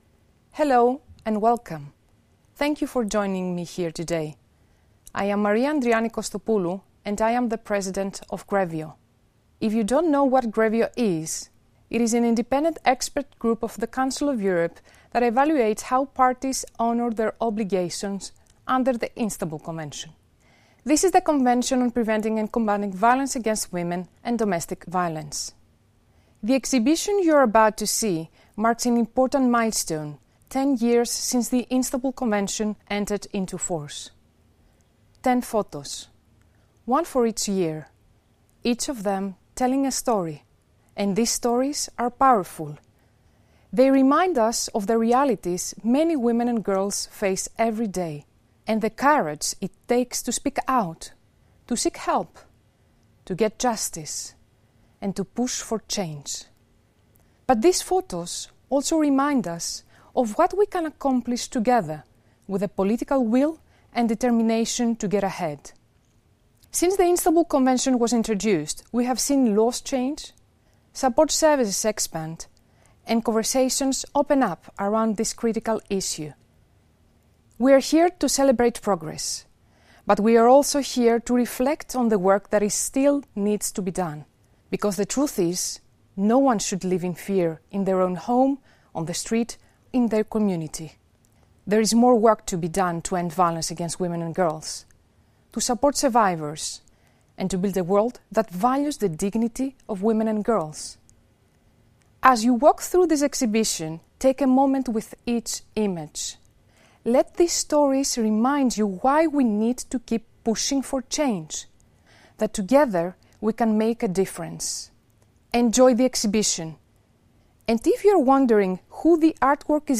link to listen to GREVIO President, Maria Andriani Kostopoulou, narrate the story behind the 10 photographs and let her insights guide you during your virtual tour.
speechEN.mp3